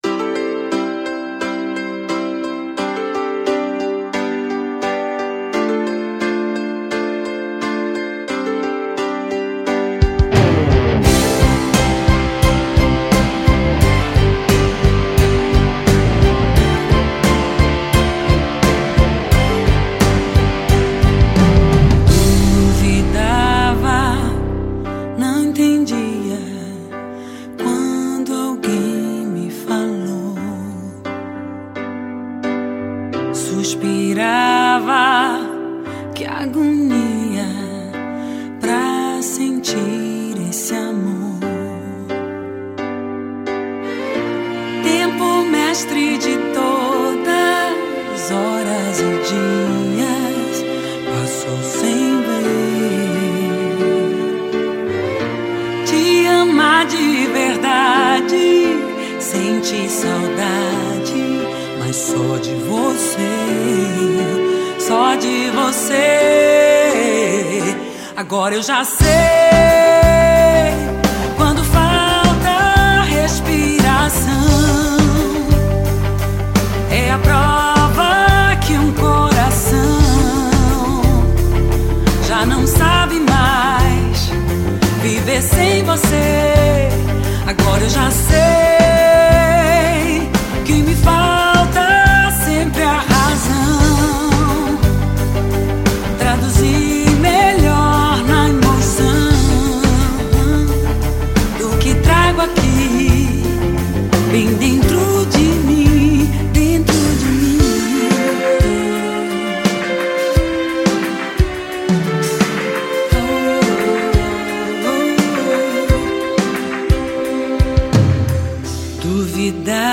cantora